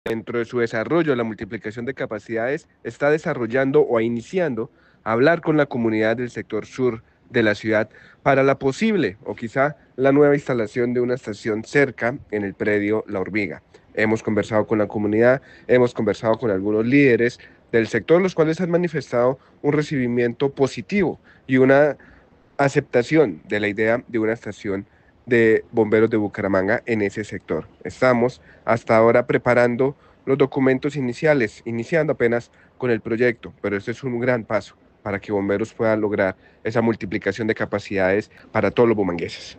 Diego Orlando Rodríguez, director de Bomberos de Bucaramanga